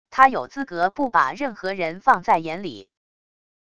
他有资格不把任何人放在眼里wav音频生成系统WAV Audio Player